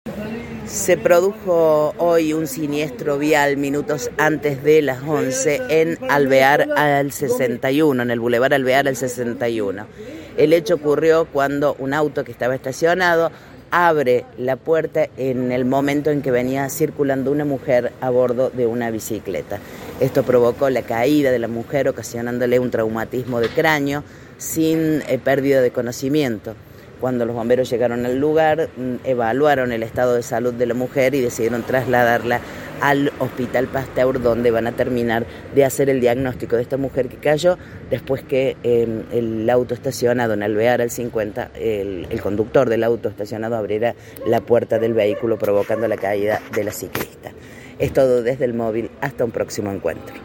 Audio: Informe